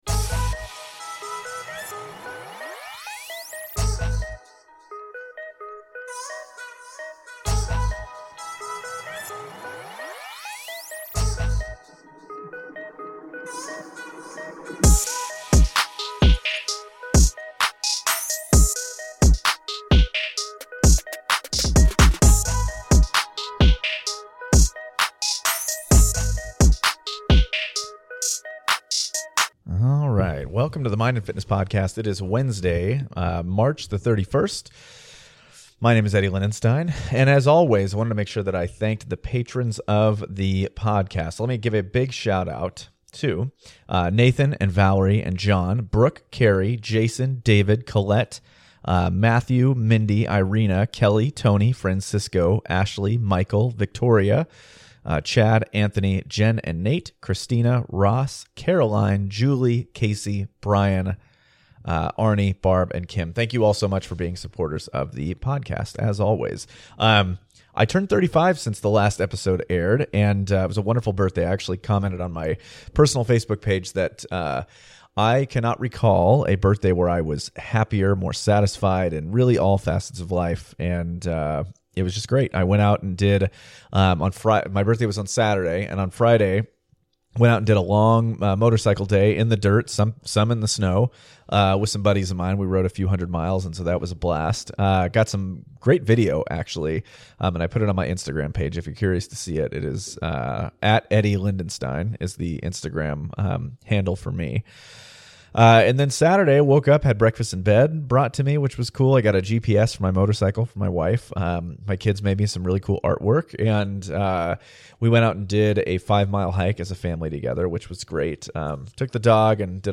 Question and Answers today!